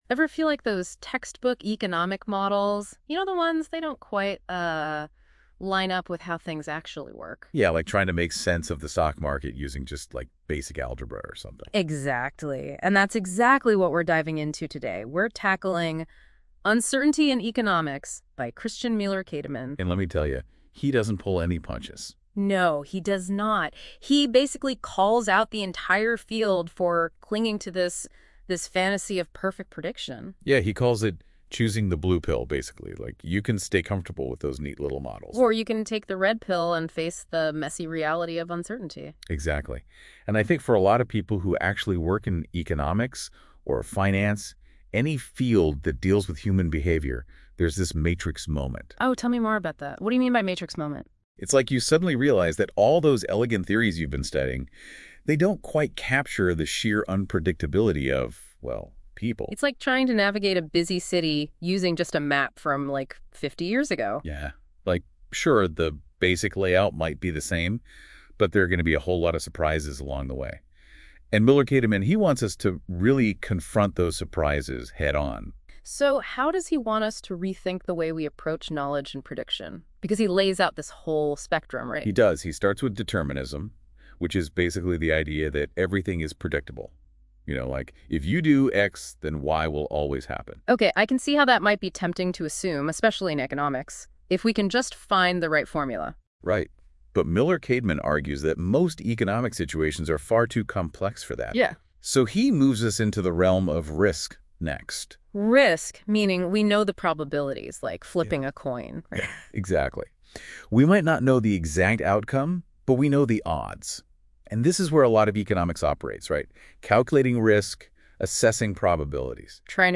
Special feature: Uncertainty and Economics goes podcast! Enjoy a deep dive into the role of uncertainty in the economy and in economics powered by AI.